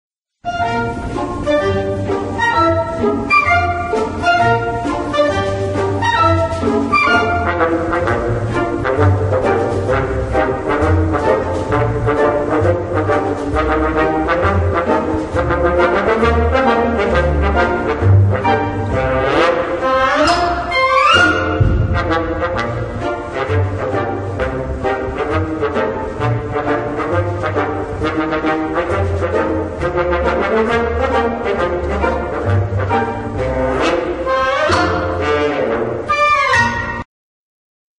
light piece of music
A short low quality sample